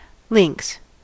Ääntäminen
Ääntäminen UK : IPA : /lɪŋks/ US : IPA : [lɪŋks] Haettu sana löytyi näillä lähdekielillä: englanti Links on sanan link monikko.